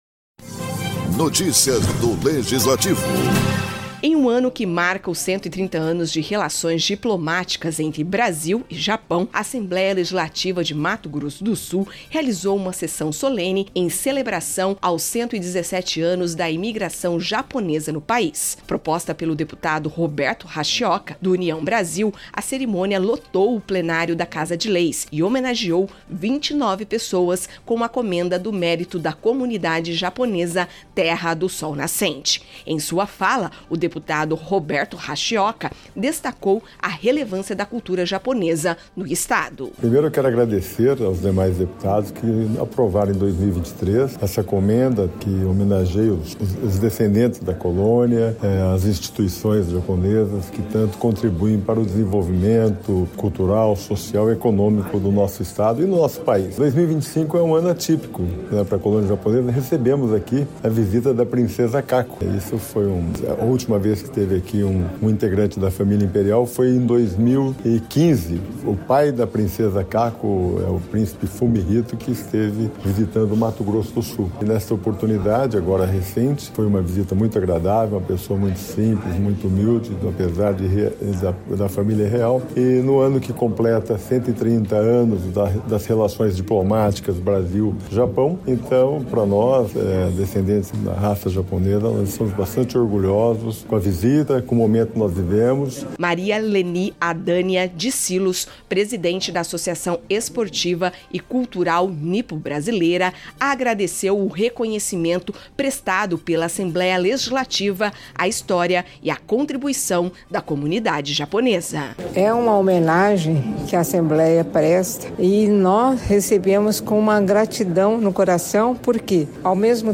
Em uma noite marcada por homenagens e celebração cultural, a Assembleia Legislativa de Mato Grosso do Sul realizou sessão solene em comemoração aos 117 anos da imigração japonesa no Brasil.